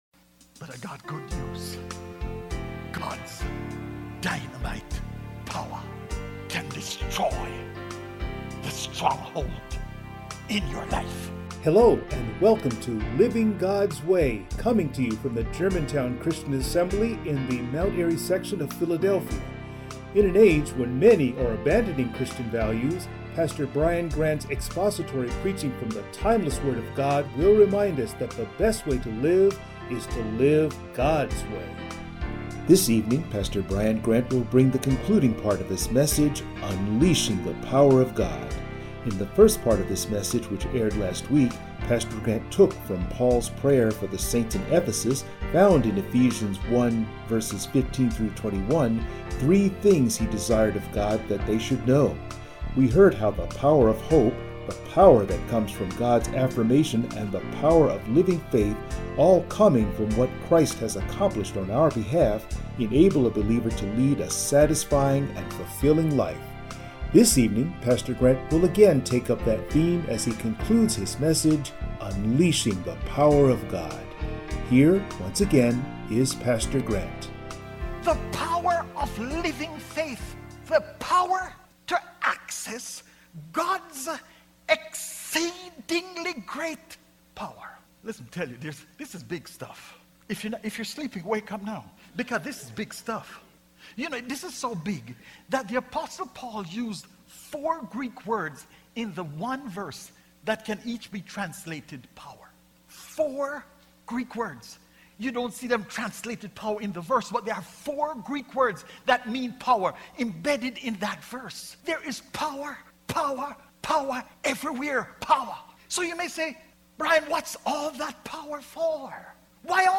Passage: Ephesians 1:15-21 Service Type: Sunday Morning